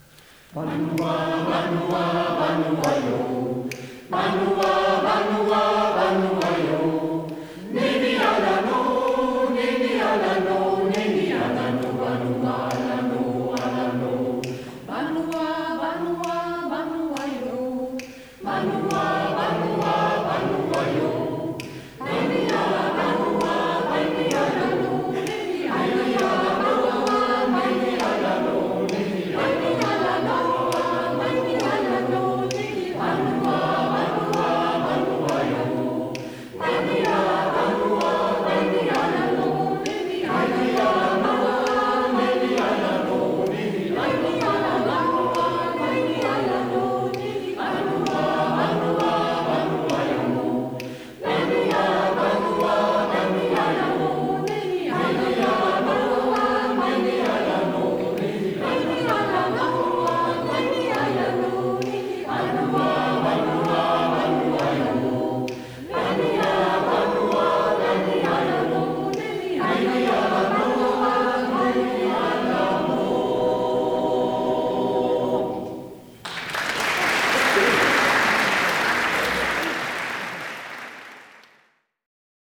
Chorkonzerte
Banuwa Round (Konzert im Antoniushaus)